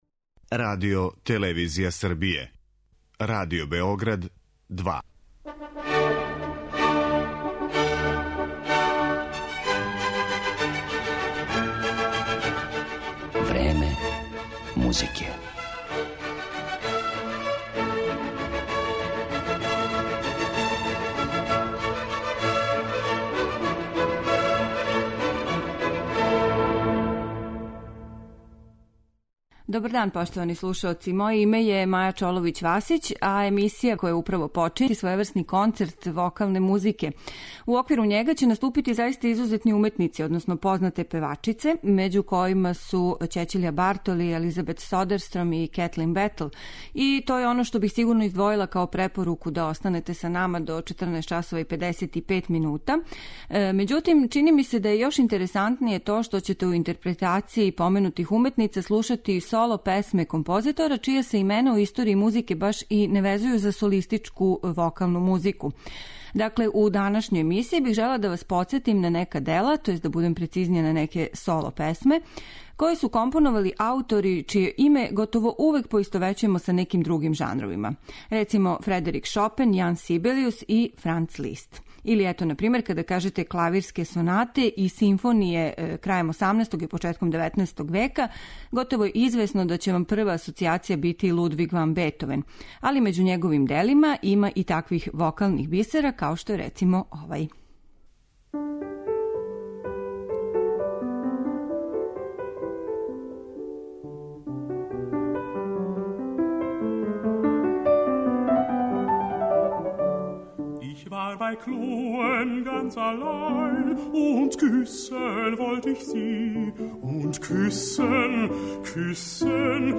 Соло песме познатх композитора